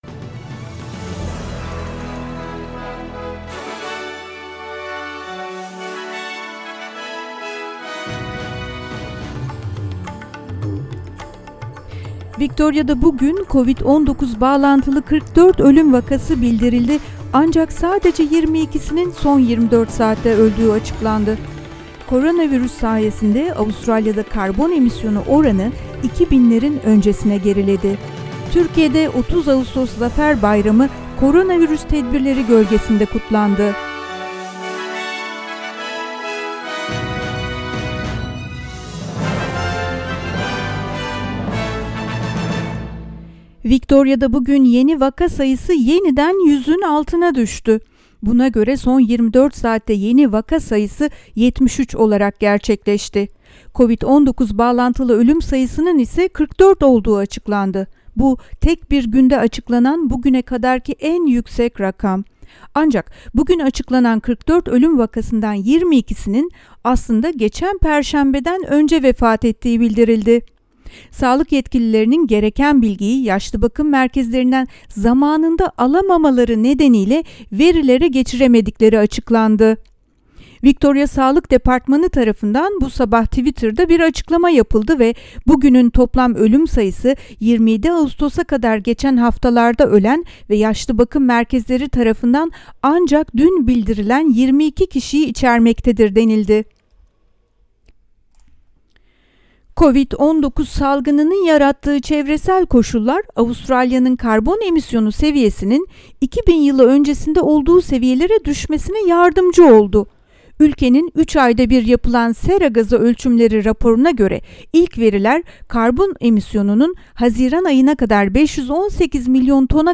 SBS Türkçe Haberler 31 Ağustos